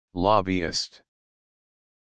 Audio Pronunciation of Lobbyist
lobbyist.mp3